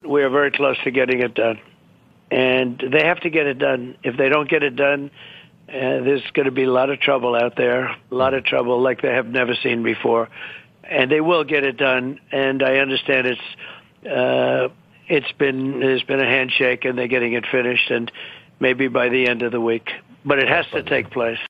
🚨טראמפ בראיון טלפוני עסקה חייבת לקרות, וזה יקרה, אם הם (החמאס) לא יעשו את זה יהיו הרבה צרות בחוץ